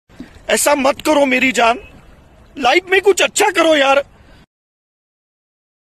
aisa mat karo meri jaan Meme Sound Effect